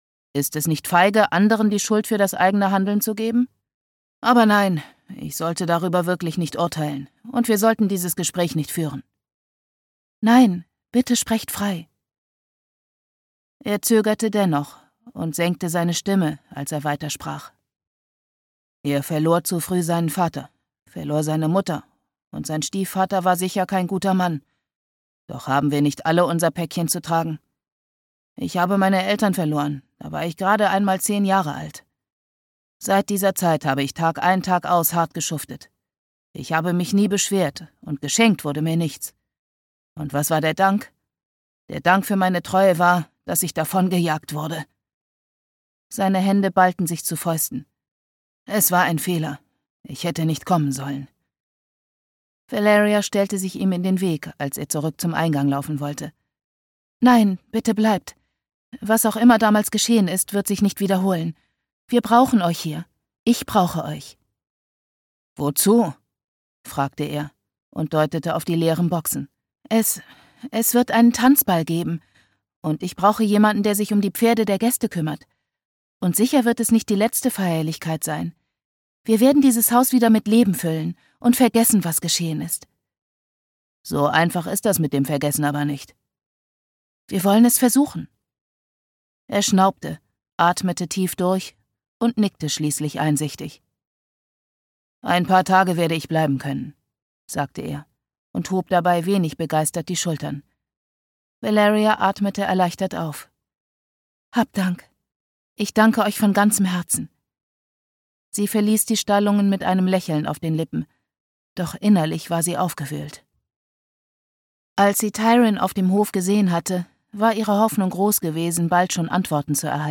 Being Beastly. Der Fluch der Schönheit (Märchenadaption von »Die Schöne und das Biest«) - Jennifer Alice Jager - Hörbuch